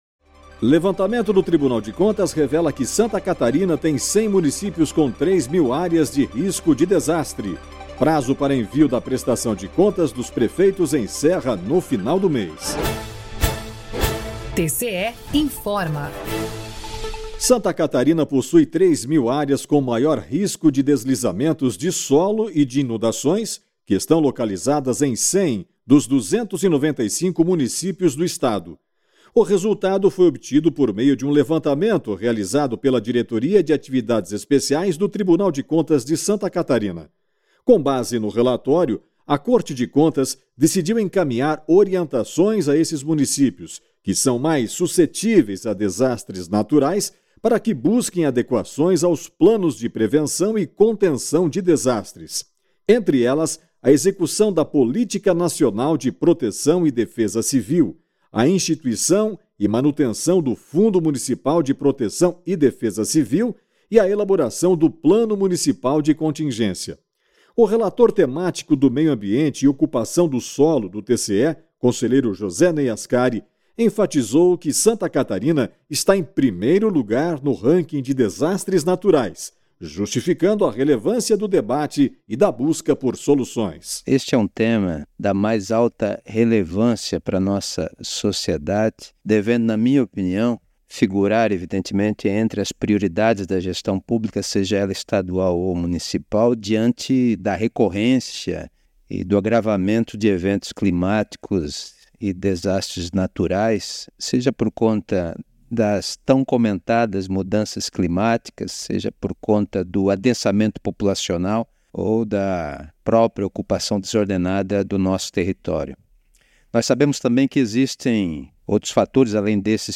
VINHETA TCE INFORMA